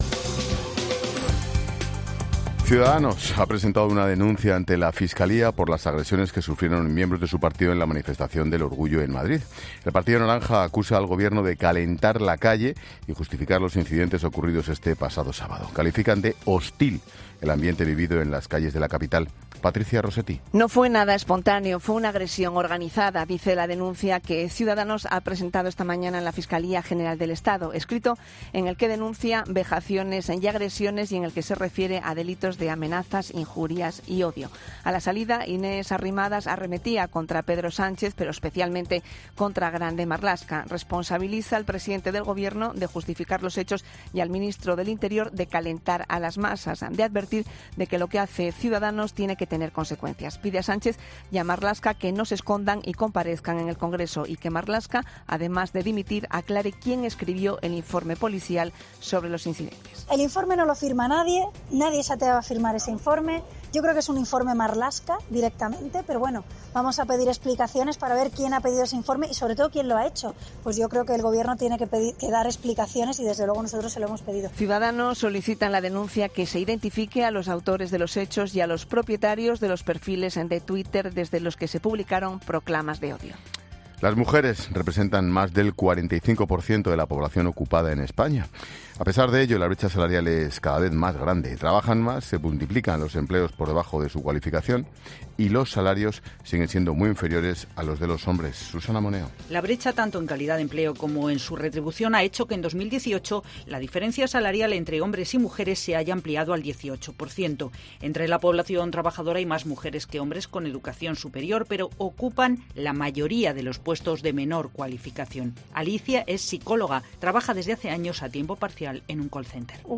Boletín de noticias de COPE del 10 de julio de 2019 a las 21:00 horas